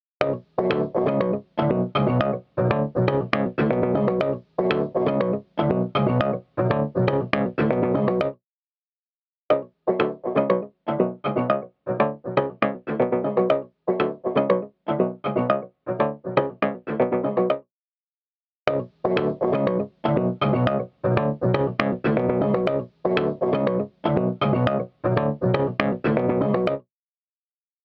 EChannel | Synth | Preset: The Crusher